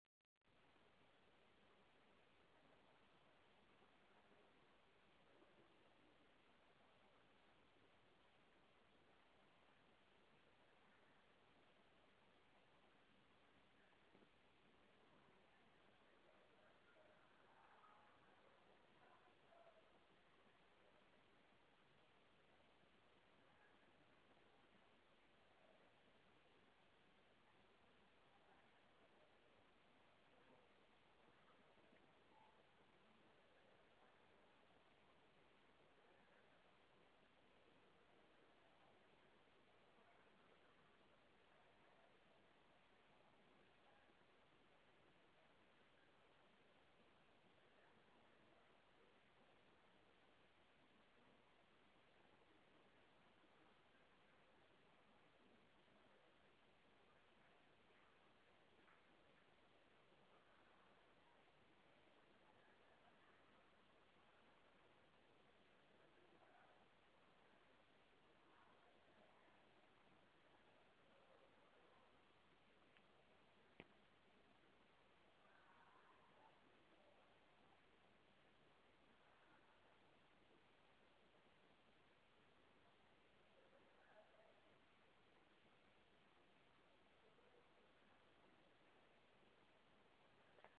crickets and YMCA sounds in the village